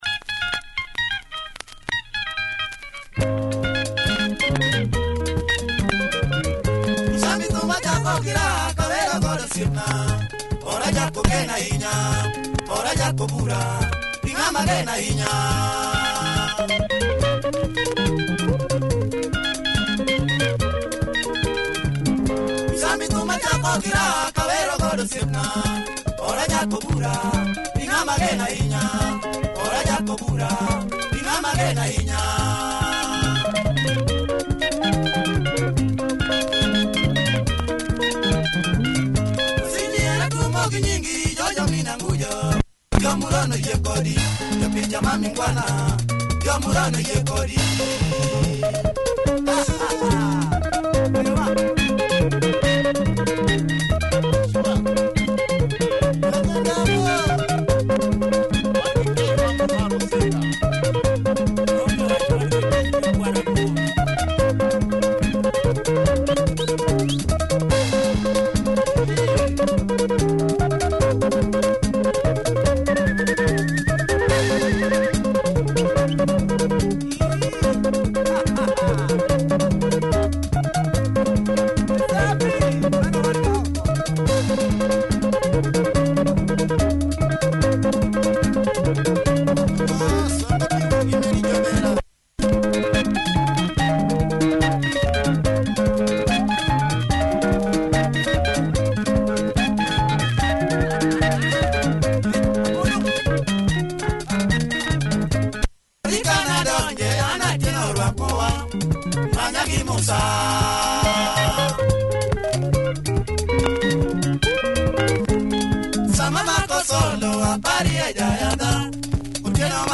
Heavy LUO stepper!